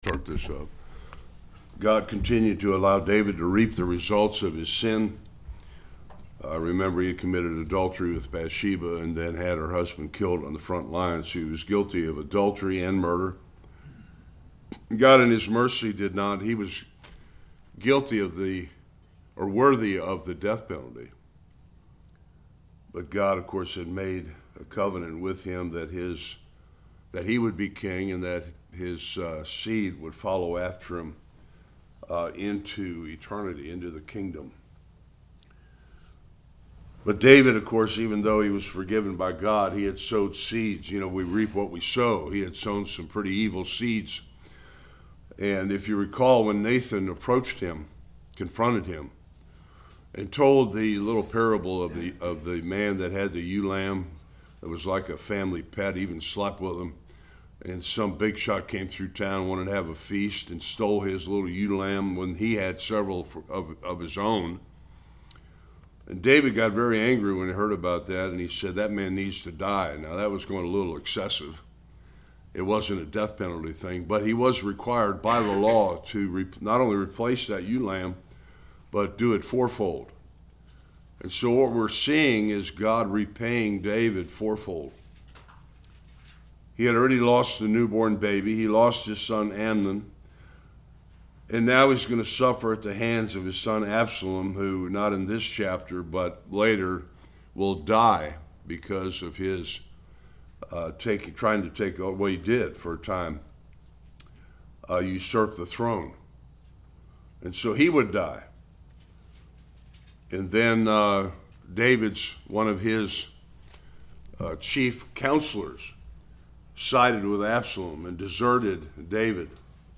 1-23 Service Type: Sunday School King David’s son Absalom steals the hearts of the people and usurps his father’s throne.